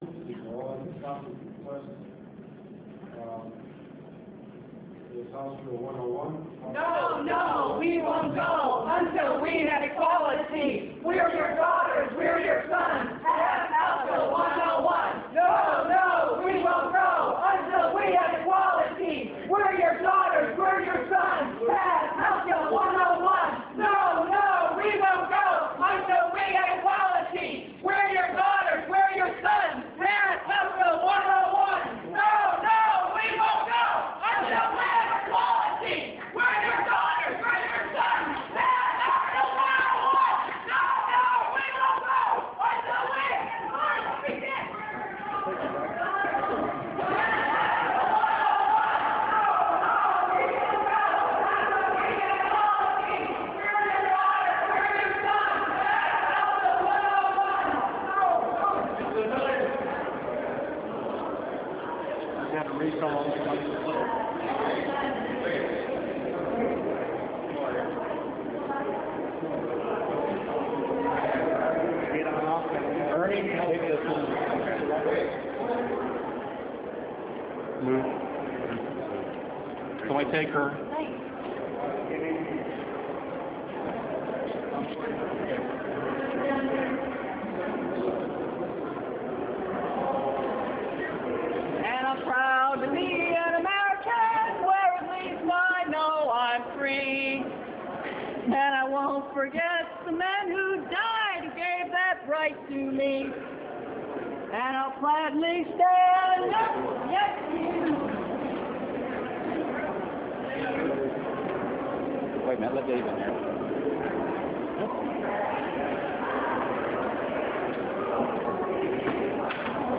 Video of 7 demonstrators chanting in support of House Bill 101 at the Illinois Senate while being arrested, Wed. May 9, 2001. House Bill 101 would extend protection from discrimination to LGBT people.